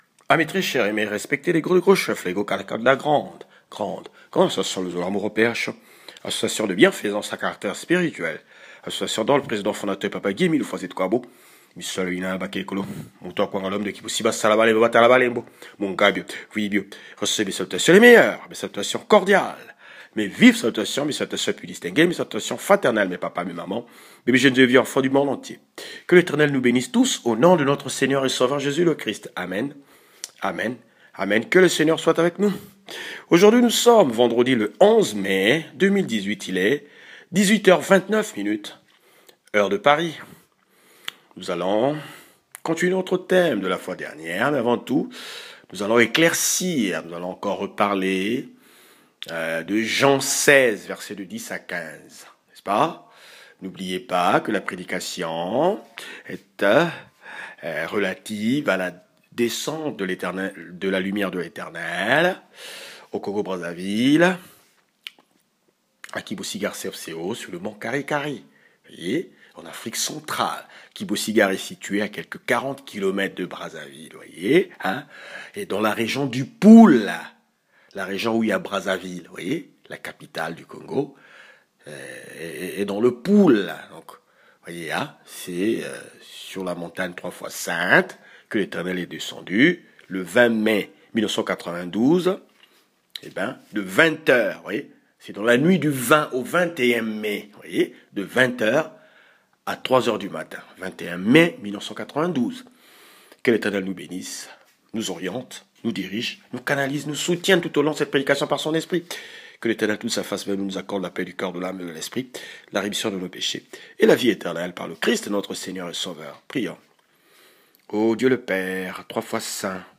Ecouter la Louange